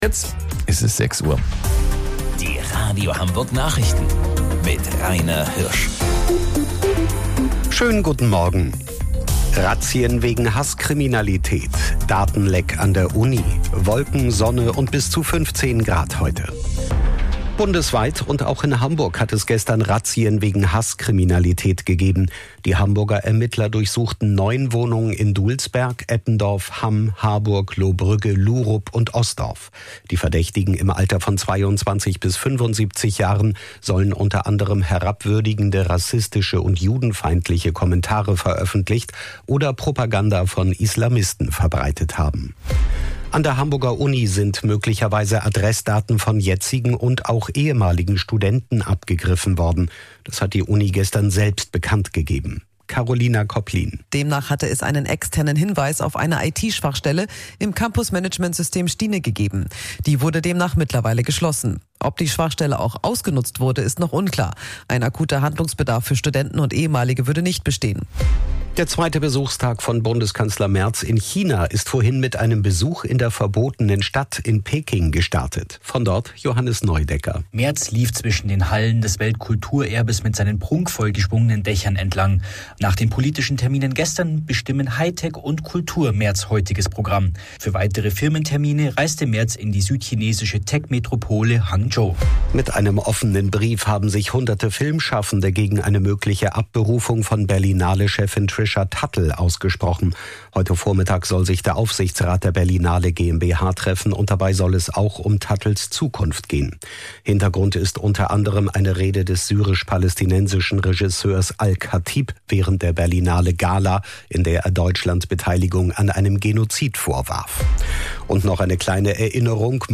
Radio Hamburg Nachrichten vom 26.02.2026 um 06 Uhr